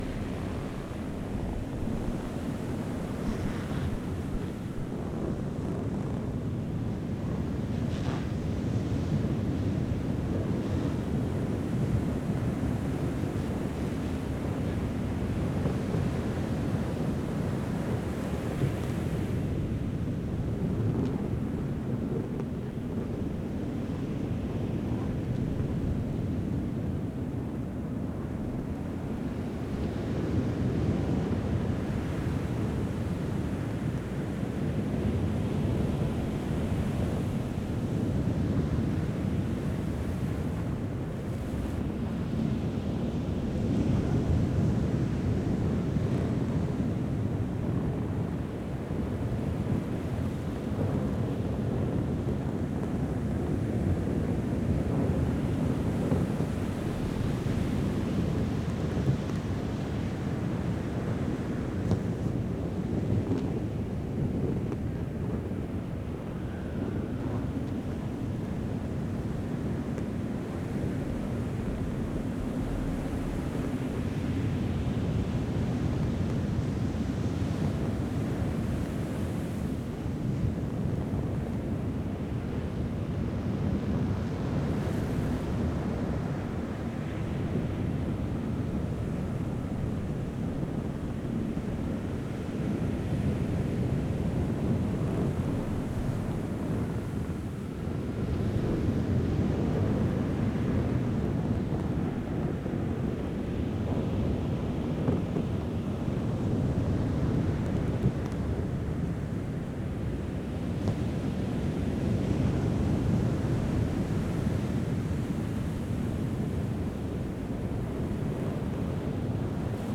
I had a request for an extended version of the parade balloon end so that I could make a loop of the chill part at the end.
inflation inflatable balloon parade relaxing relaxation calming asmr asmrish chill furry audio sound big round air rubber squeak creak soundscape sfx only loop